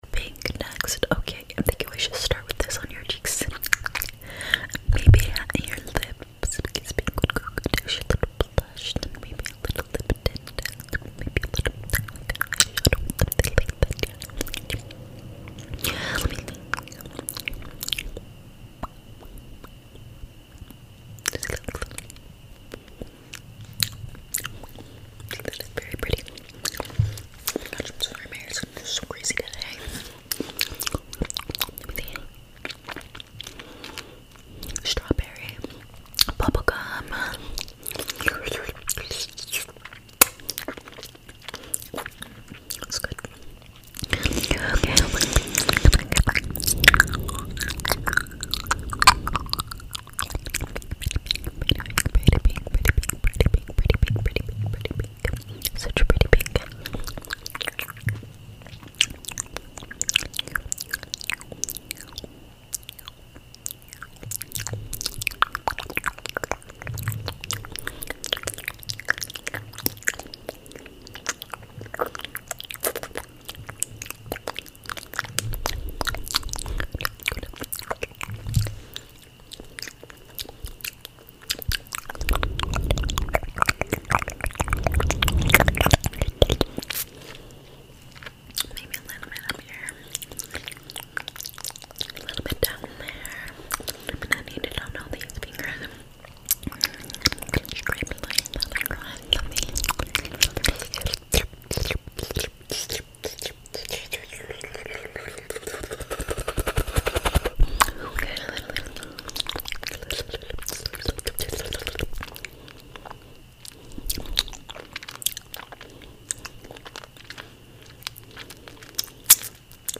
ASMR MOUTH SOUNDS 👅 sound effects free download